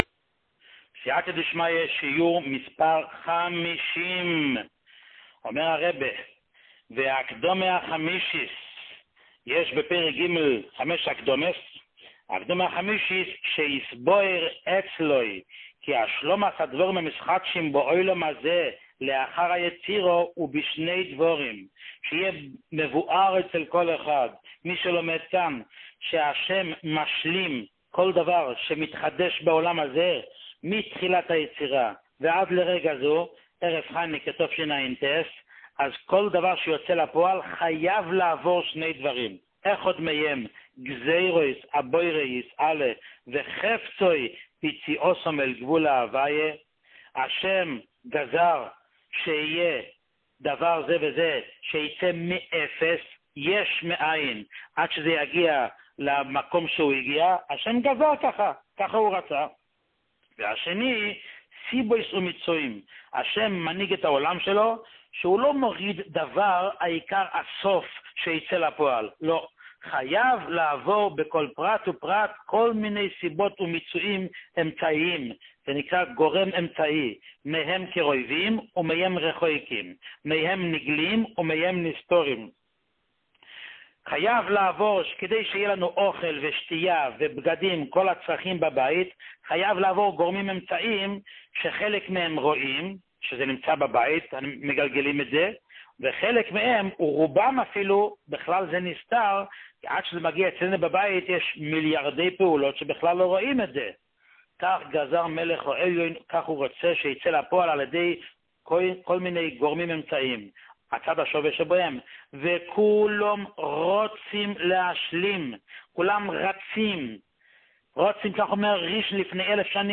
שיעורים מיוחדים
שיעור 50